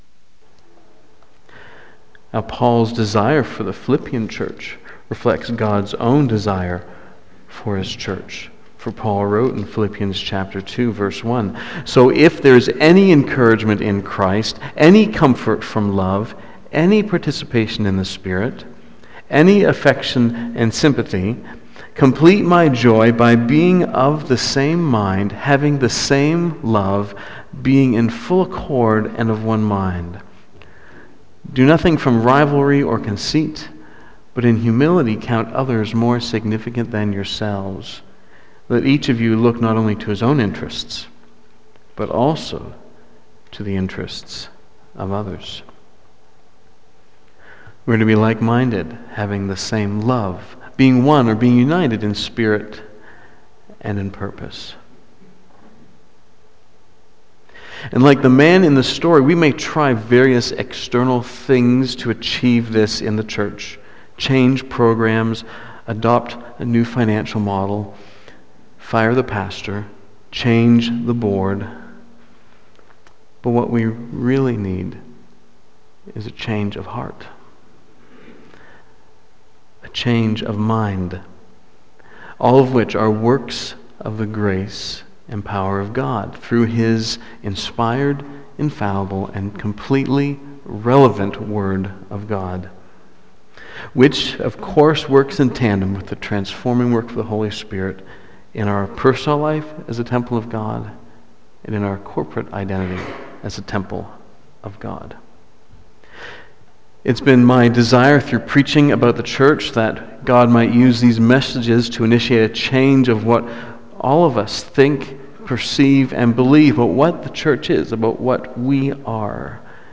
Don’t Judge One Another BACK TO SERMON LIST Preacher